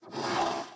sounds / mob / horse / skeleton / idle2.mp3